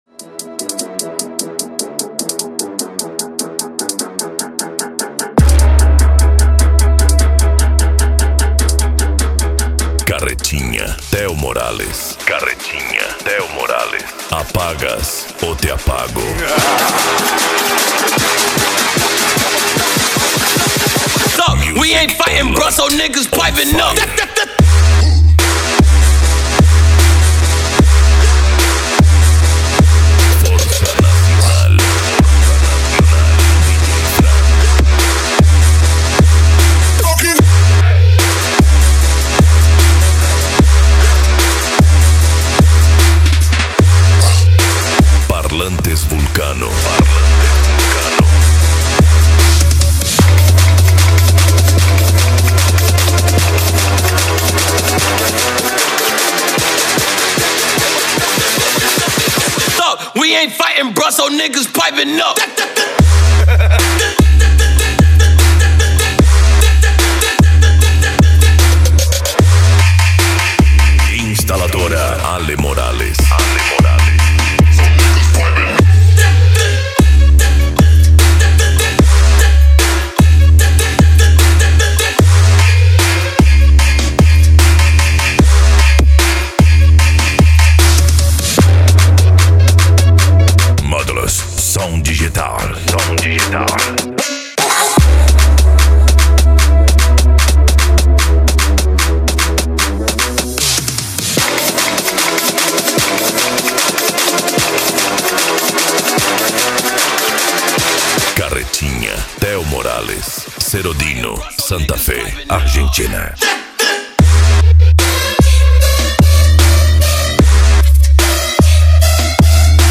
Psy Trance
Remix